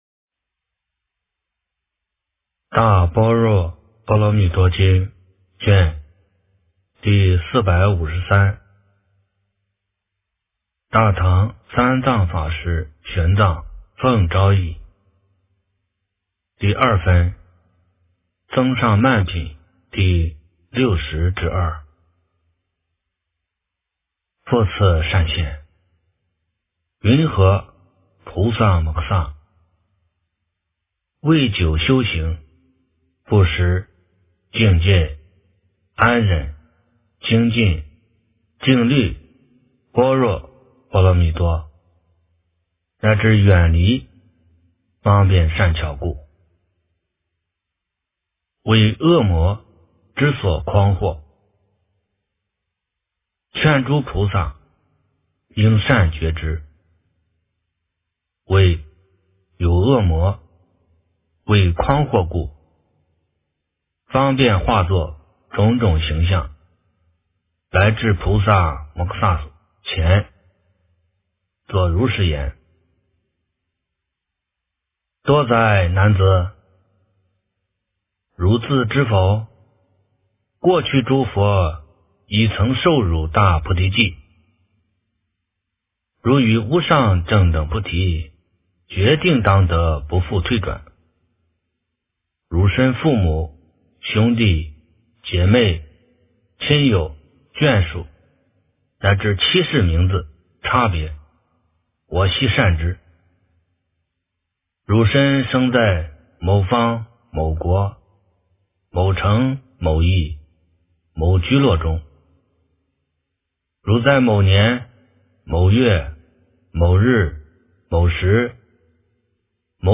大般若波罗蜜多经第453卷 - 诵经 - 云佛论坛